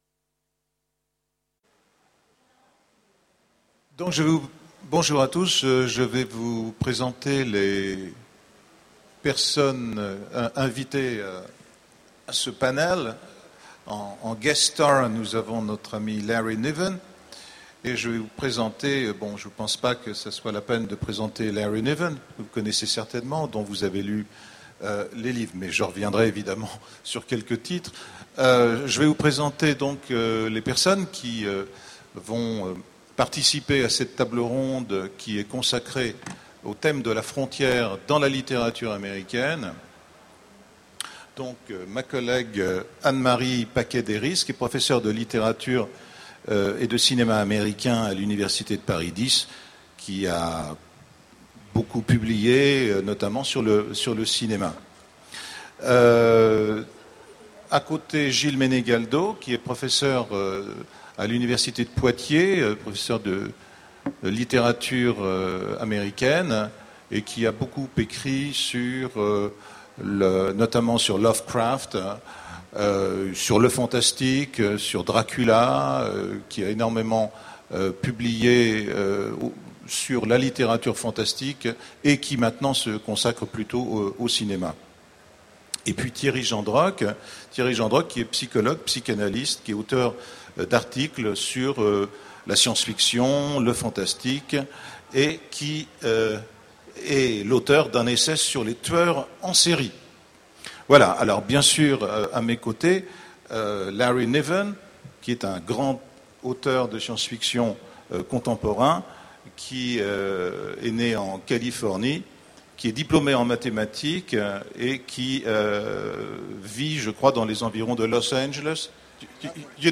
Voici l'enregistrement de la conférence " Le Mythe de la frontière dans la littérature américaine " aux Utopiales 2010.